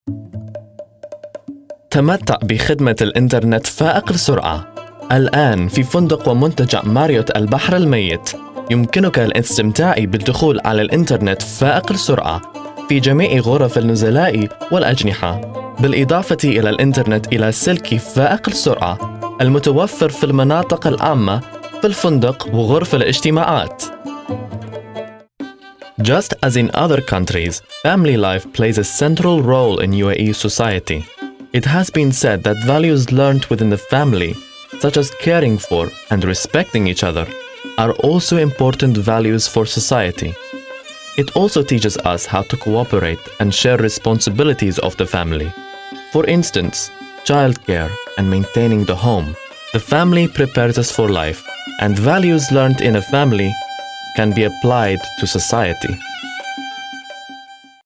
Commercial Reel (Arabic Accent)
Commercial, Confident, Strong, Fresh, Exciting